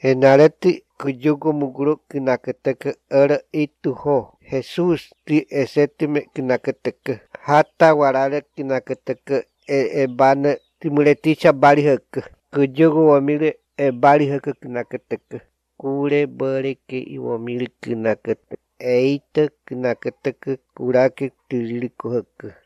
9 April 2023 at 10:53 am With the obvious pronunciation of “Jesus” in a very Spanish-sounding way, I think it is an indigenous languages from Central and/or South America.